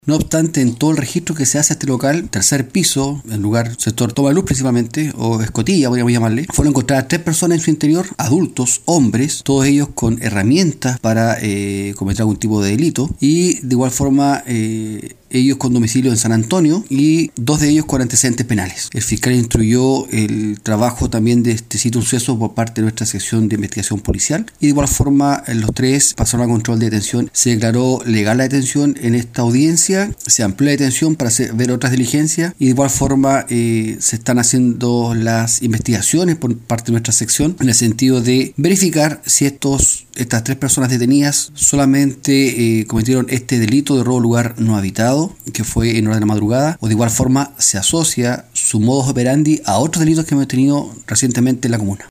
El oficial indicó que, en el operativo de registro al interior del local se detuvo a tres individuos quienes se encontraban provistos de diversos elementos para perpetrar el robo: